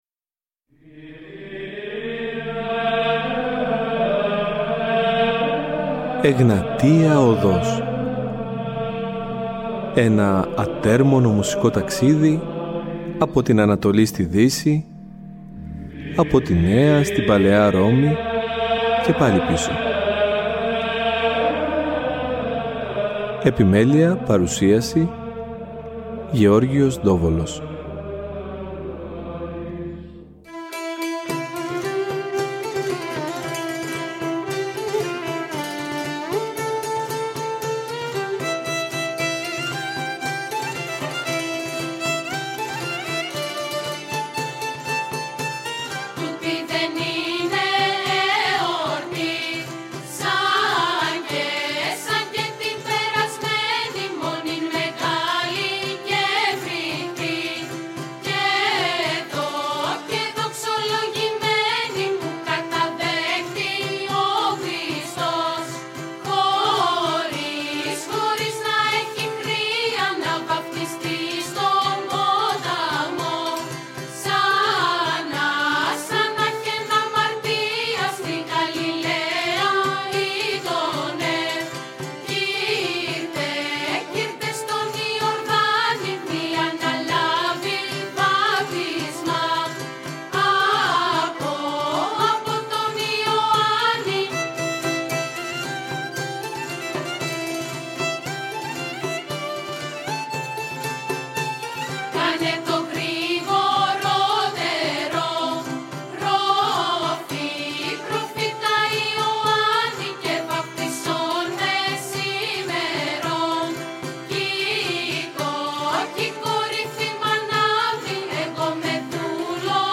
Βυζαντινη Μουσικη